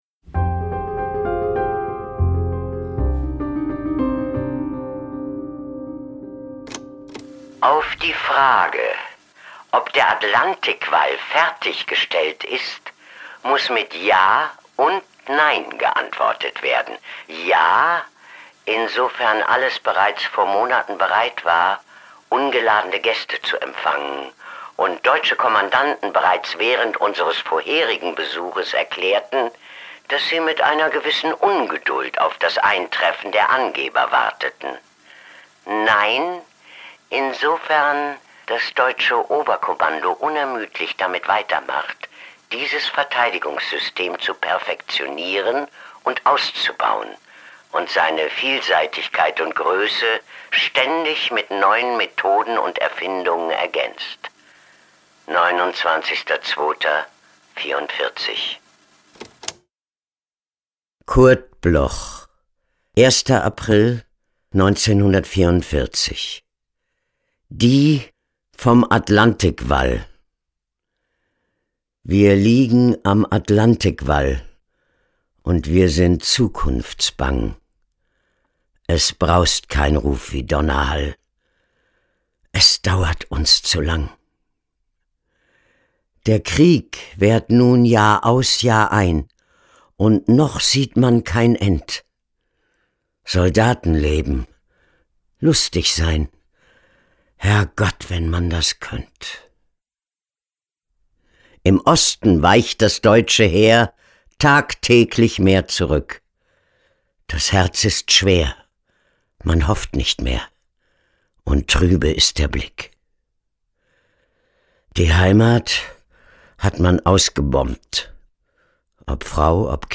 vorgetragen von Mechthild Großmann
Mechthild-Grossmann-Die-vom-Atlantikwall-mit-Musik.m4a